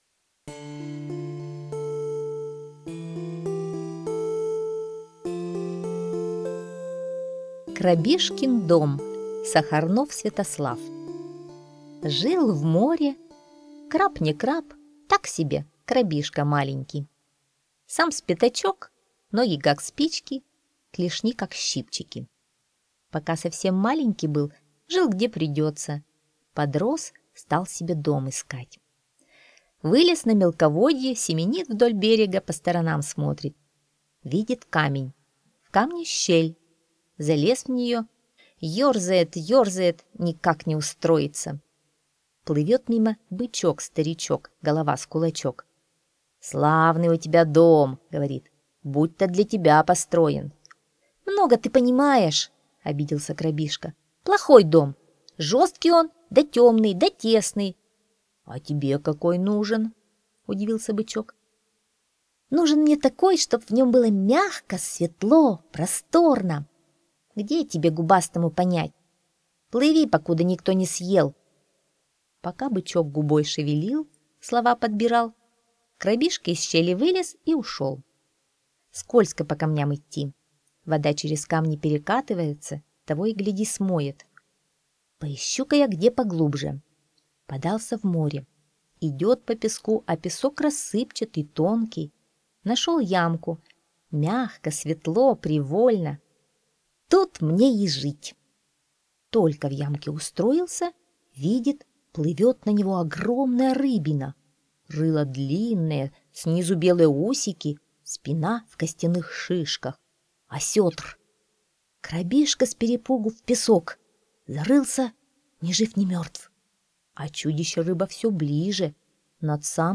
Крабишкин дом - аудиосказка Сахарнова С.В. Жил в море крабишка.